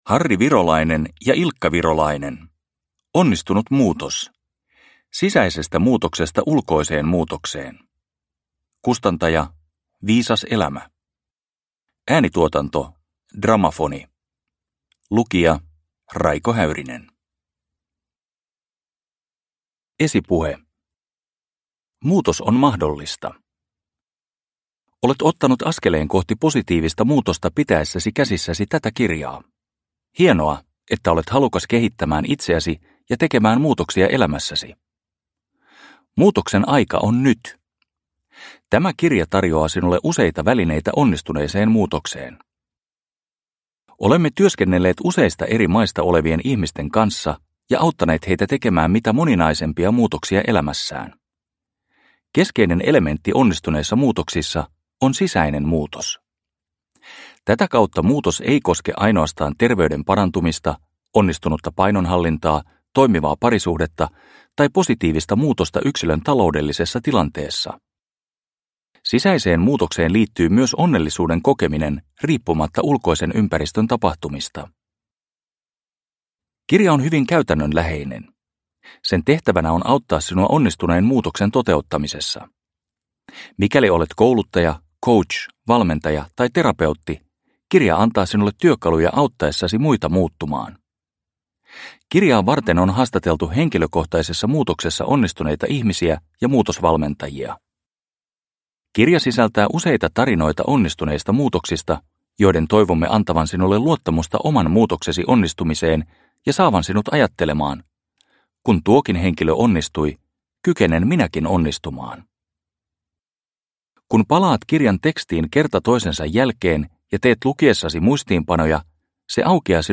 Onnistunut muutos – Ljudbok – Laddas ner